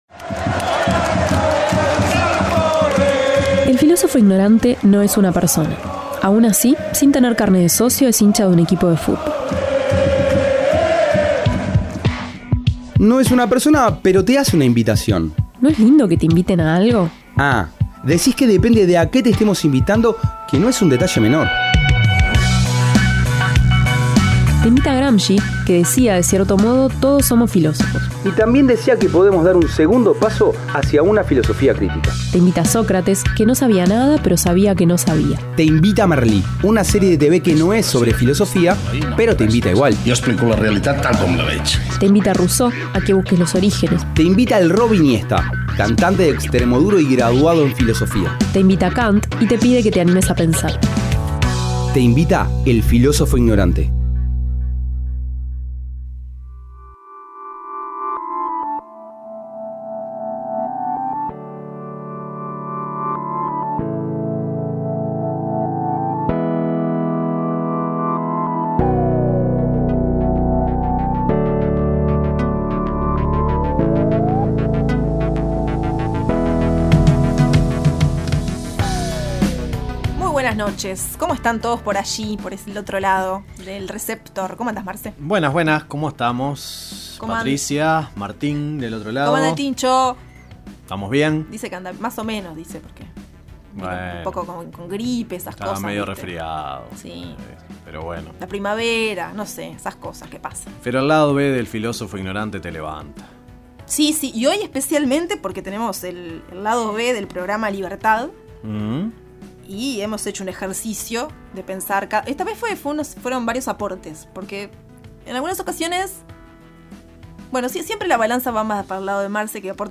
una playlist que fluye libre por lenguas y estilos.